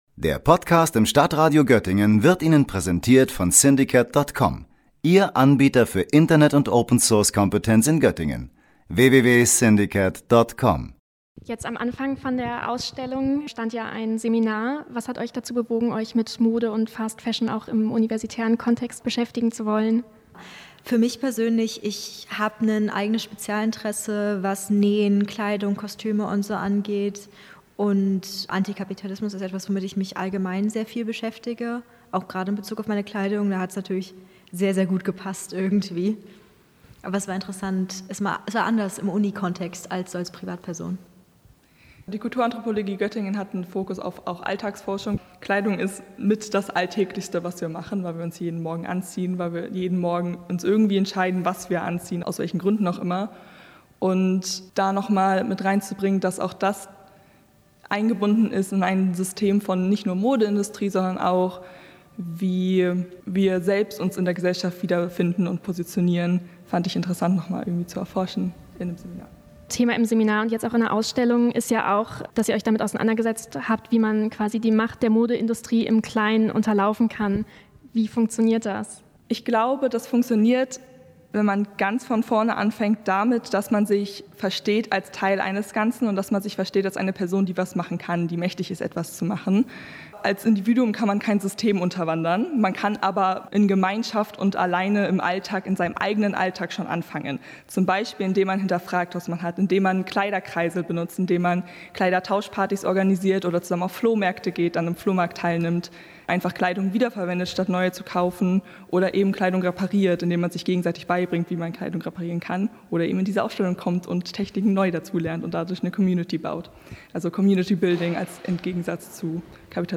Das Modesystem kritisch hinterfragen und Ansätze finden, wie es weiterentwickelt werden kann – darum ging es in dem Seminar. Aber anders als so oft sonst bleiben die Ergebnisse nicht hinter verschlossenen Seminar-Raumtüren: Vier Masterstudierende haben die Ausstellung „Gegen_Mode“ konzipiert, die aktuell im Forum Wissen zu sehen ist.